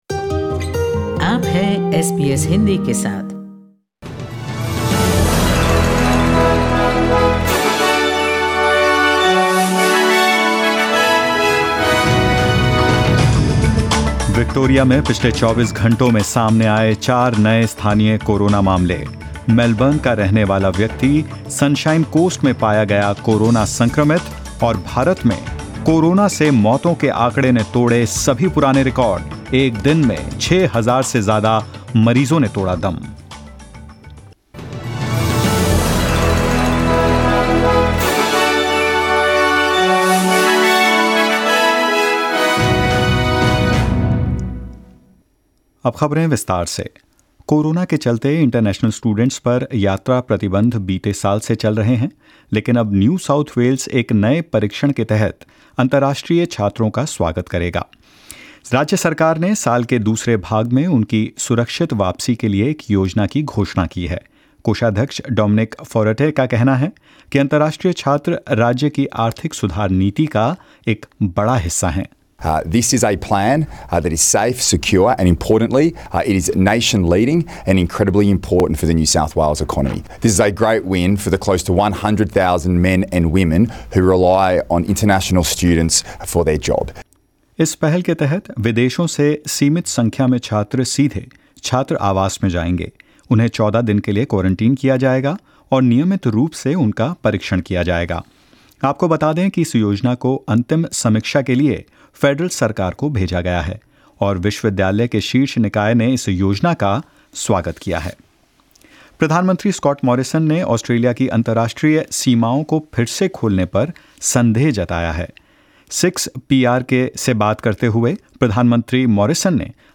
In this latest SBS Hindi News bulletin of Australia and India: India records the highest daily COVID-19 deaths; New Zealand's captain, Kane Williamson ruled out of the second test against England and more.